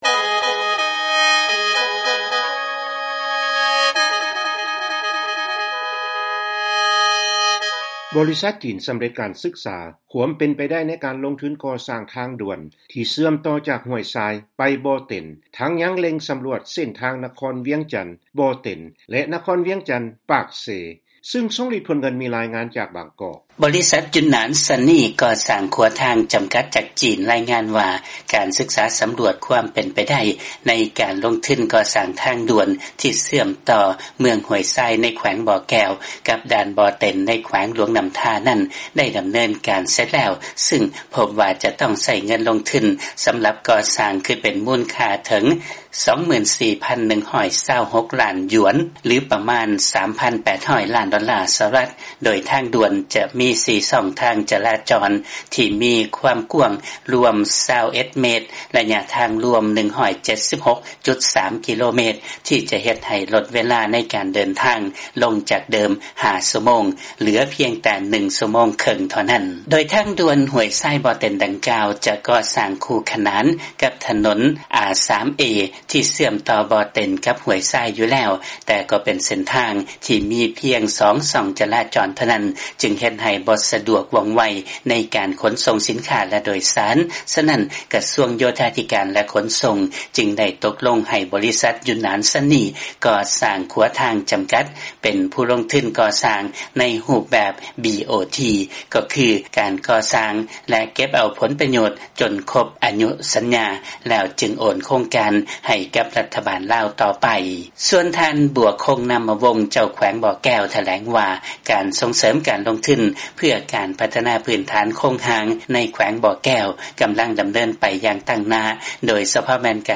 ເຊີນຟັງລາຍງານ ບໍລິສັດຈີນ ສໍາເລັດການສຶກສາຄວາມເປັນໄປໄດ້ ໃນການລົງທຶນກໍ່ສ້າງທາງດ່ວນທີ່ເຊື່ອມຕໍ່ຈາກຫ້ວຍຊາຍໄປບໍ່ເຕັນ